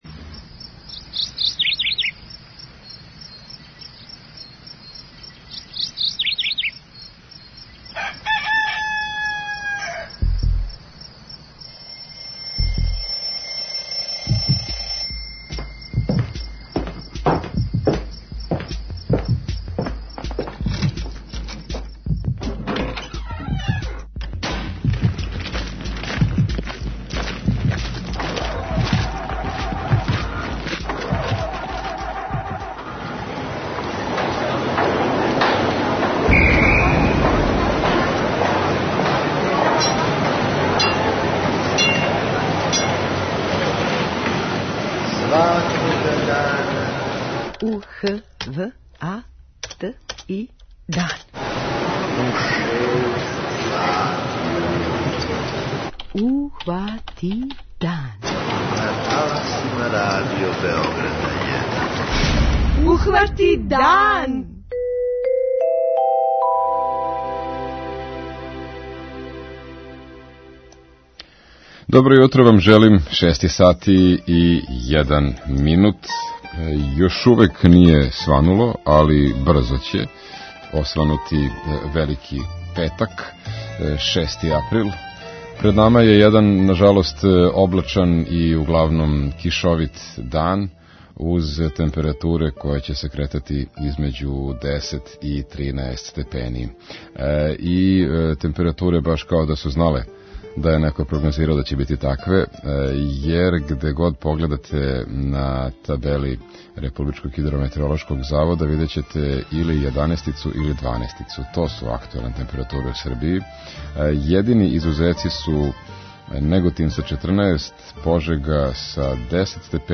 06:03 Јутарњи дневник, 06:35 Догодило се на данашњи дан, 07:00 Вести, 07:05 Добро јутро децо, 08:00 Вести, 08:10 Српски на српском, 08:45 Каменчићи у ципели
Јутарњи програм на Велики петак, као што то и доликује, биће добрим делом посвећен предстојећем празнику - од наших репортера из Ниша, Новог Сада и Косовске Митровице чућемо у каквој атмосфери се дочекује Ускрс.